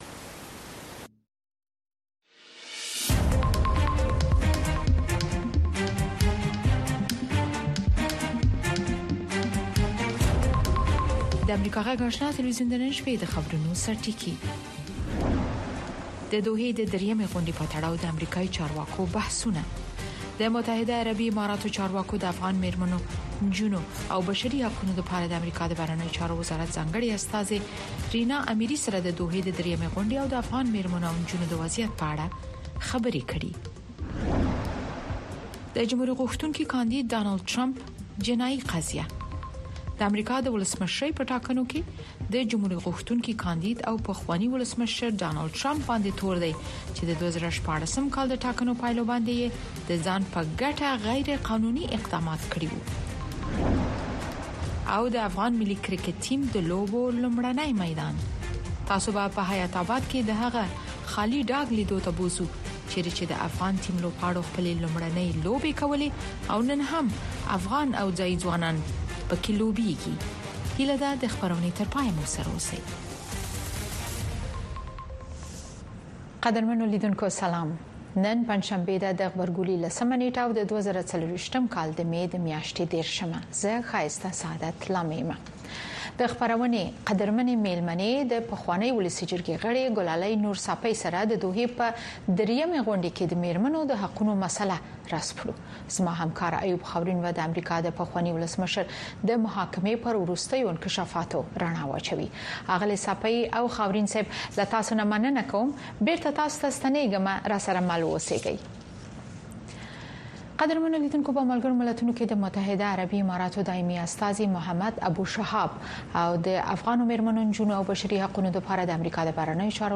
د اشنا په خبري خپرونو کې د شنبې څخه تر پنجشنبې پورې د افغانستان، سیمې او نړۍ تازه خبرونه، او د ټولې نړۍ څخه په زړه پورې او معلوماتي رپوټونه، د مسولینو او کارپوهانو مرکې، ستاسې غږ او نور مطالب د امریکاغږ راډیو، سپوږمکۍ او ډیجیټلي شبکو څخه لیدلی او اوریدلی شی.